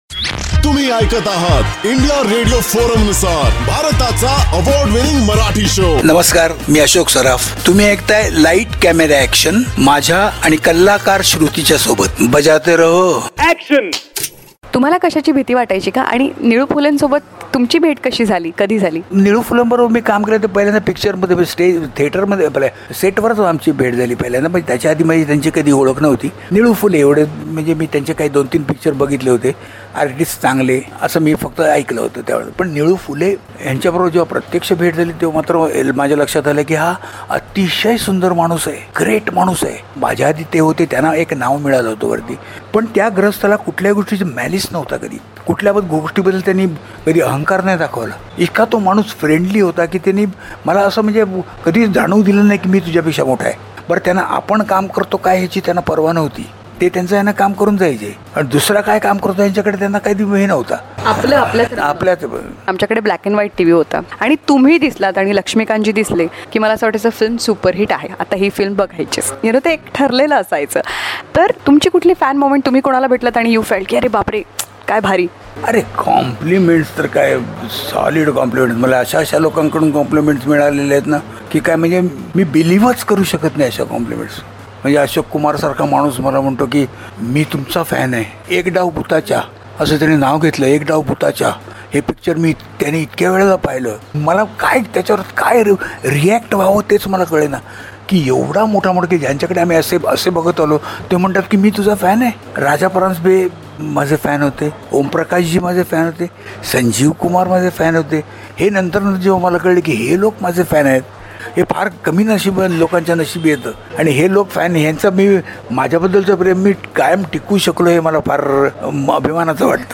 VETRAN ACTOR ASHOK SARAF TALKS ABOUT TALKS ABOUT WHAT HAPPENED WHEN HE MET NILU PHULE